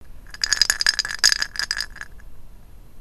あご君土鈴 その5の音(mp3音声ファイル,4秒,74kバイト)